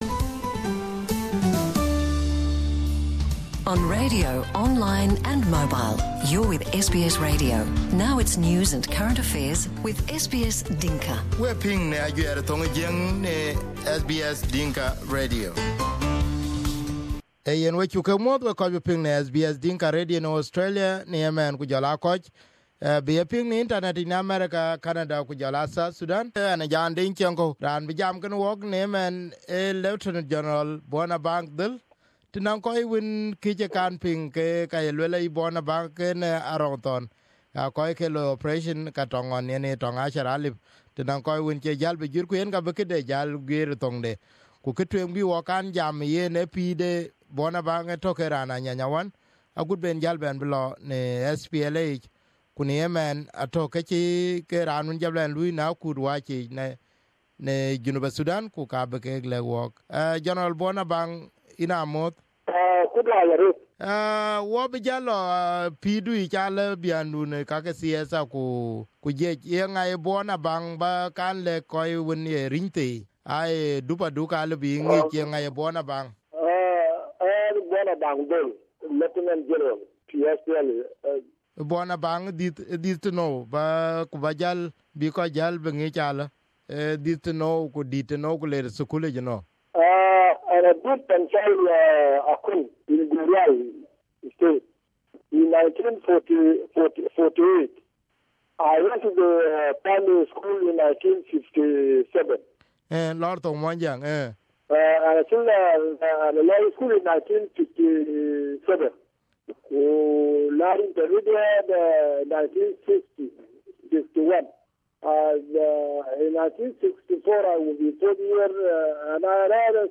We interview Bona to help understand the reason many of them fought and whether they regret having seen the reality of independent South Sudan now? Here is the interview with General Bona for a first time on SBS Dinka Radio.